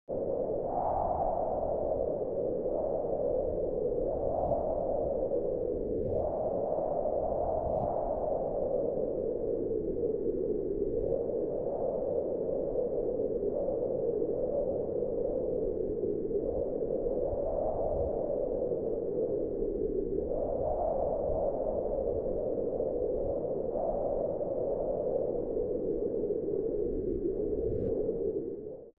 دانلود آهنگ باد 30 از افکت صوتی طبیعت و محیط
دانلود صدای باد 30 از ساعد نیوز با لینک مستقیم و کیفیت بالا
جلوه های صوتی